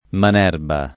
[ man $ rba ]